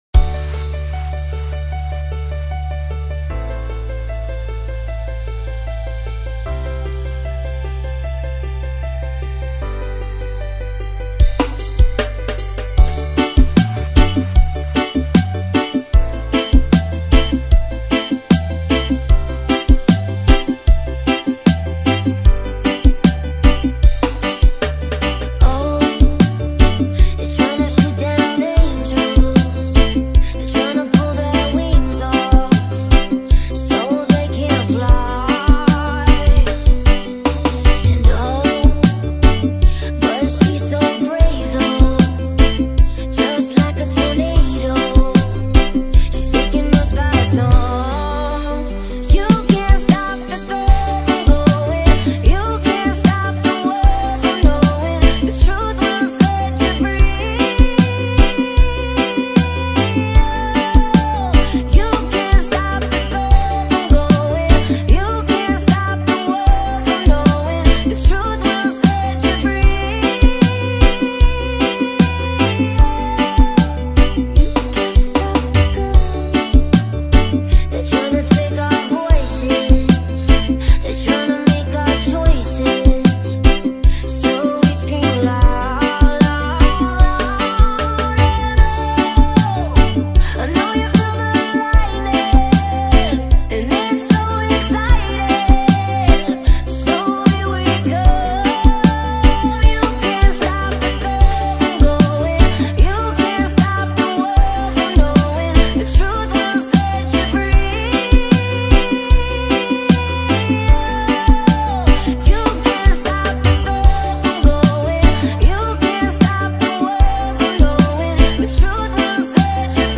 REGGAE DO MARANHAO 2021 INTERNATIONAL MELO DE MALEVOLA